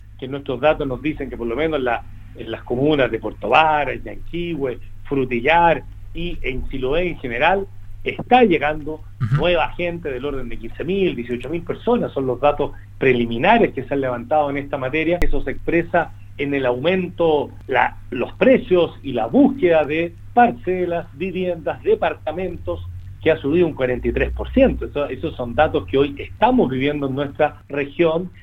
Según indicó el gobernador regional de Los Lagos, Patricio Vallespín, en entrevista con Radio Sago, la mayoría de las personas que están migrando al interior del país y eligen lugares en esta región tienen un poder adquisitivo que les permite comprar parcelas para instalarse. Vallespín indicó que, según los primeros datos, más de 15 mil personas han llegado a la región en el último período de tiempo.